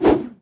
handswing4.ogg